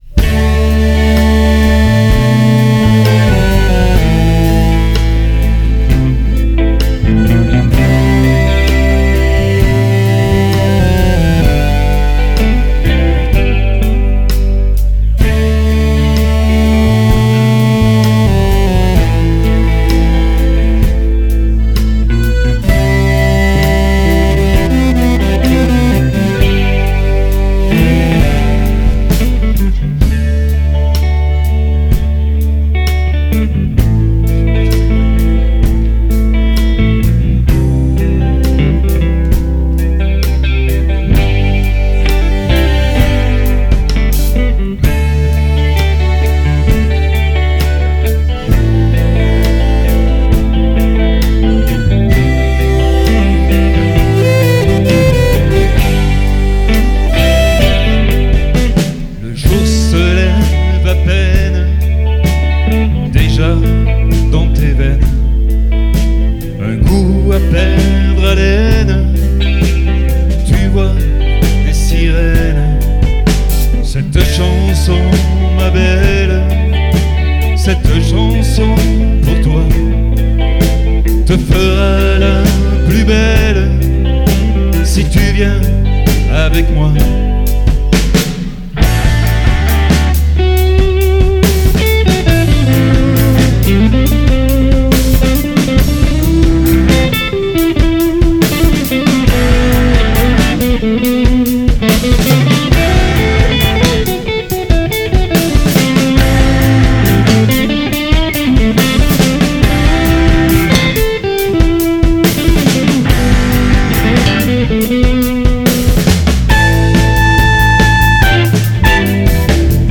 en live en 2013
aux choeurs et au sax midi
à l'accordéon,
à fond à la batterie